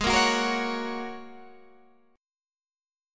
G#7sus2sus4 chord